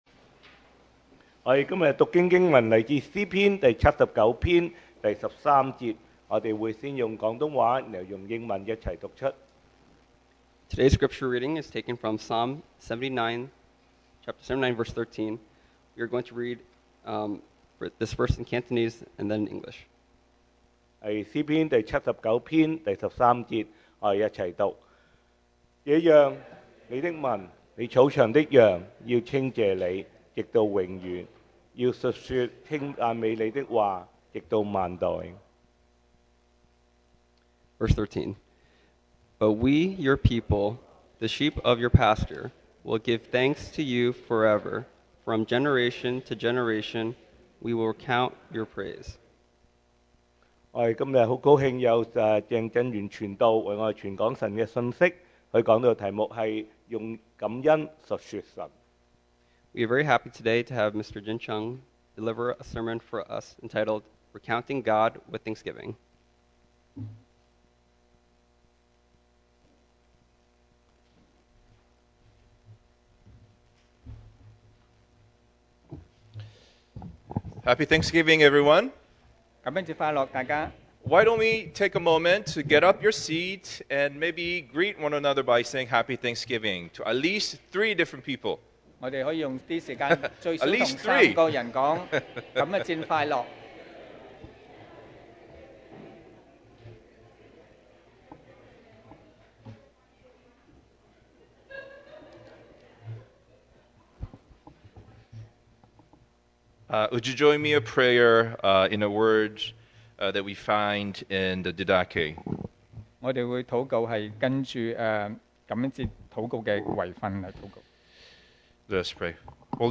Service Type: Thanksgiving Day